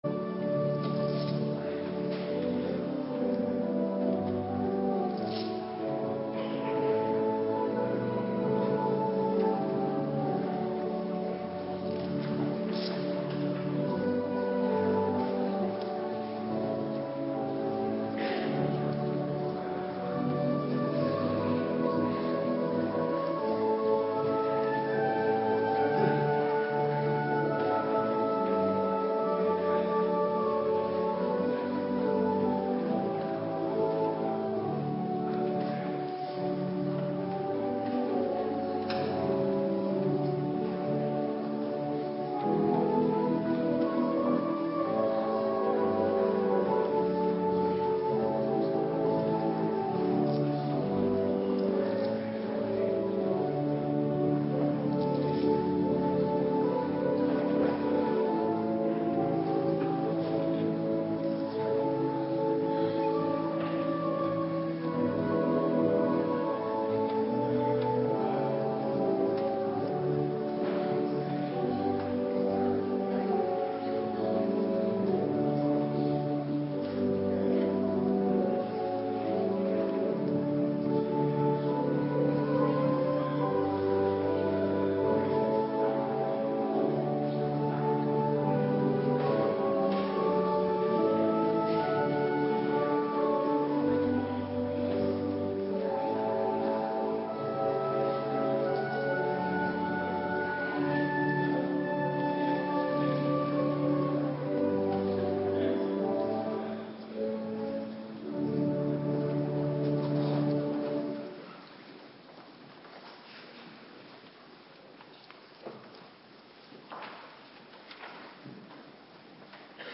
Avonddienst - Cluster A
Locatie: Hervormde Gemeente Waarder